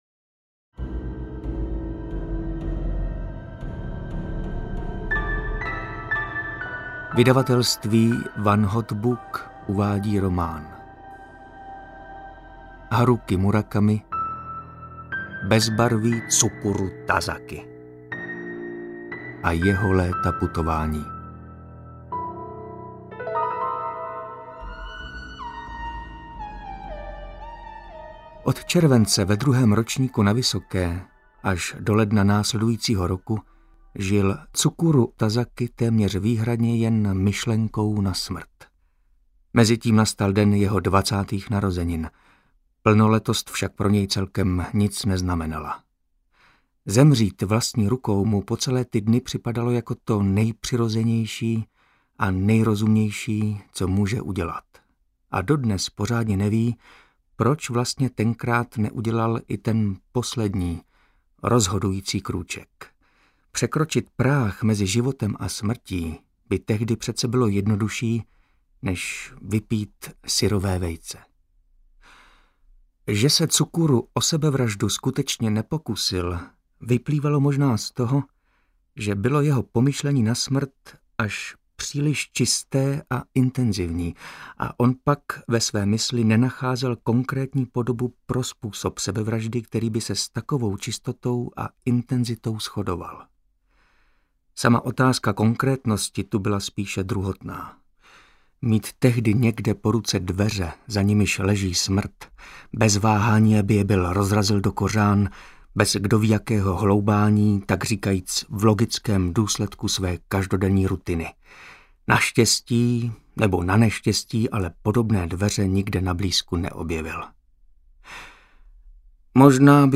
Ukázka z knihy
• InterpretMartin Myšička